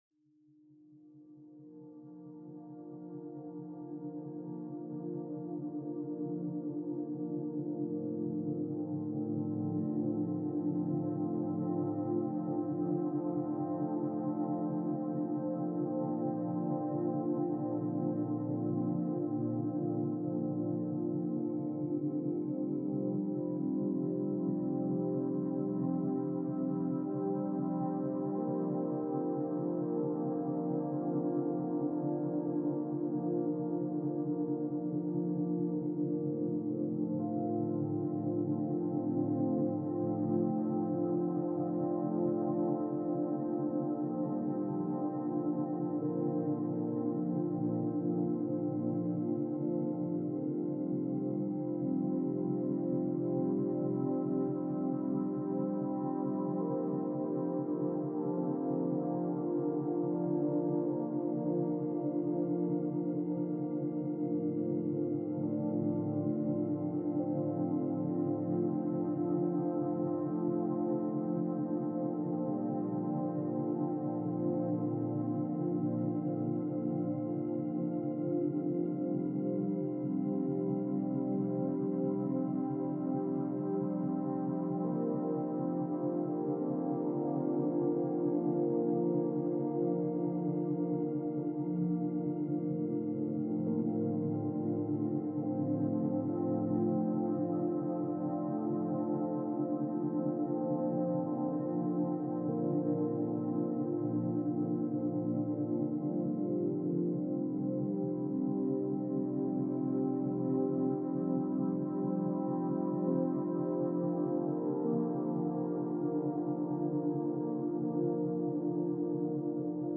Nature nocturne douce · méthode essentielle pour apprendre le soir